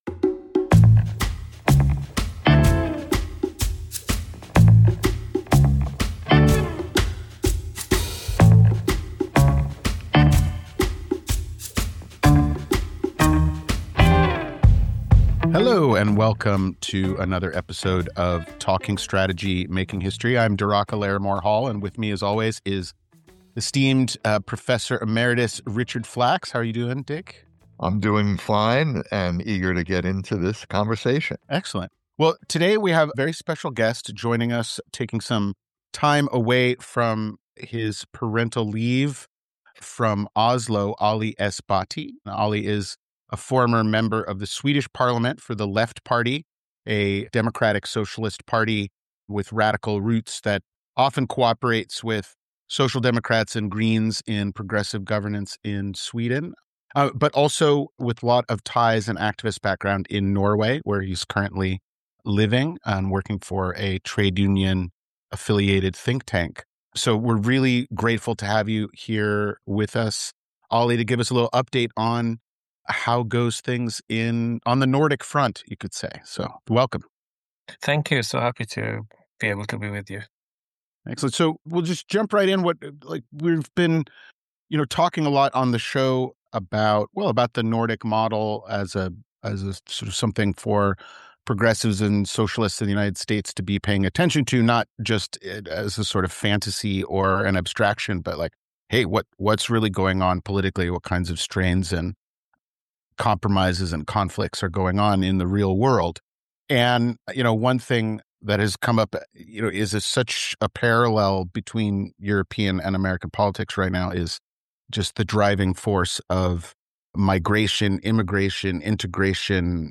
In recent years he's been a popular blogger and economic analyst, now living in Oslo, Norway from where he spoke with us.